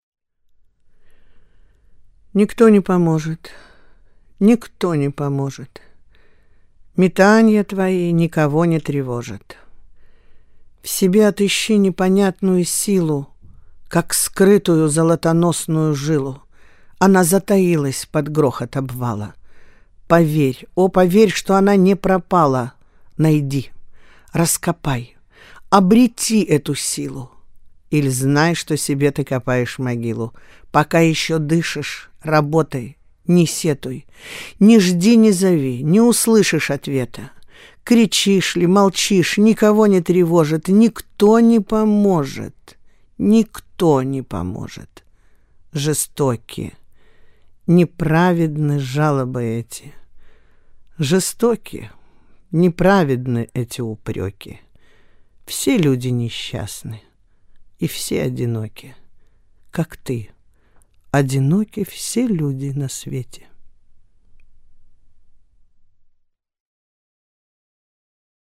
M.-Nikto-ne-pomozhet-nikto-ne-pomozhetstihi-chitaet-Svetlana-Kryuchkova-stih-club-ru.mp3